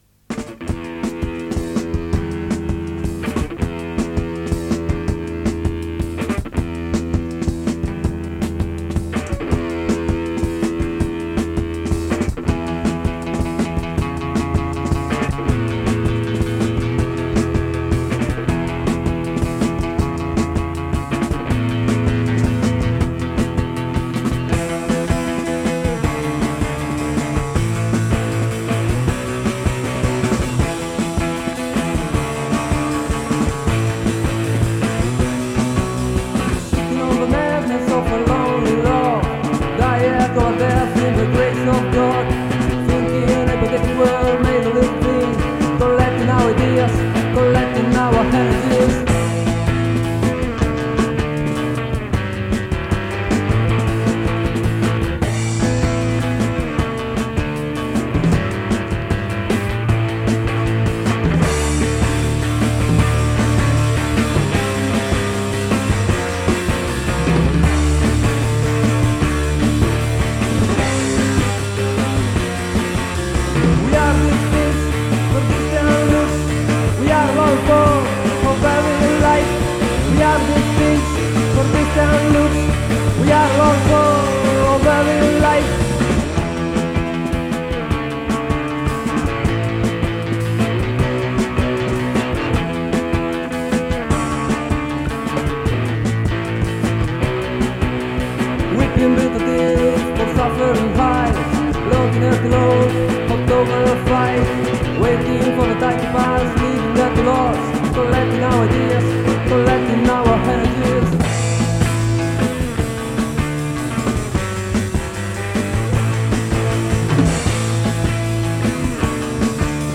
• Centro Sportivo di Lobbi (AL)